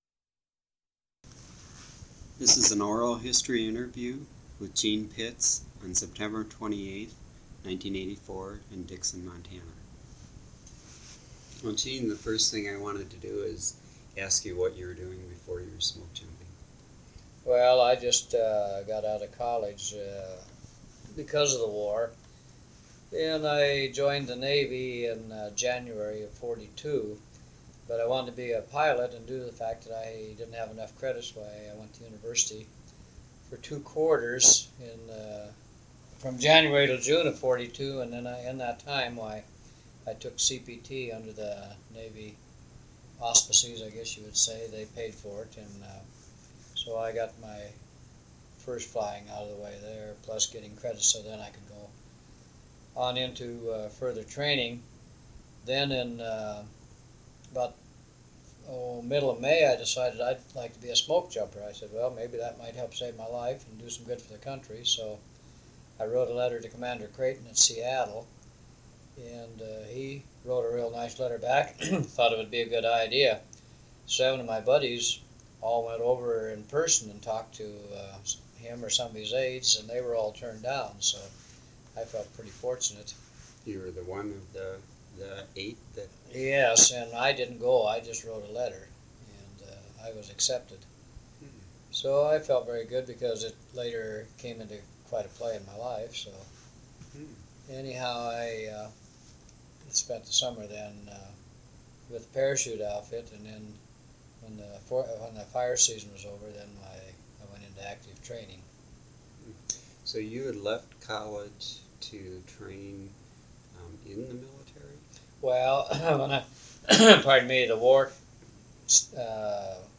Document Type Oral History
Original Format 1 sound cassette (51 min.) : analog